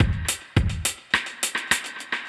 Index of /musicradar/dub-designer-samples/105bpm/Beats
DD_BeatB_105-03.wav